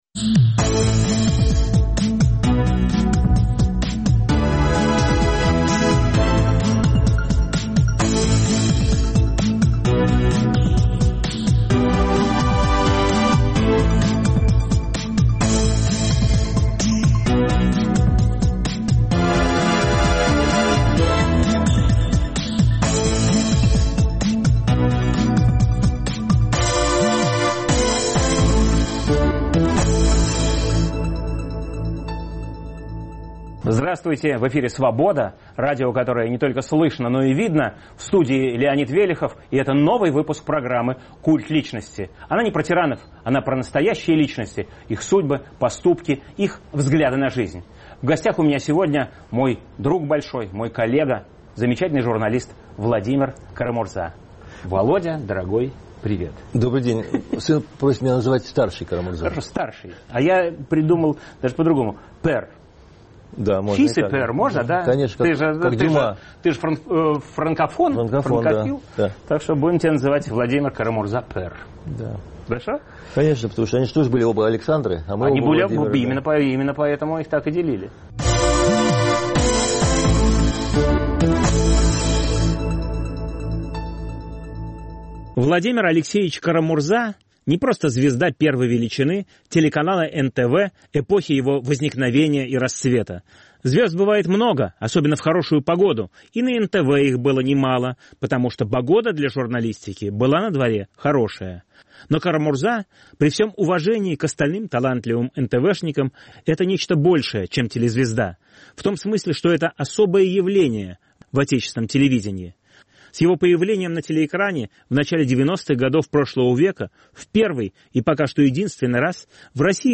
Программа о настоящих личностях, их судьбах, поступках и взглядах на жизнь. В студии знаменитый теле- и радиоведущий Владимир Кара-Мурза.